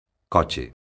coche_son.mp3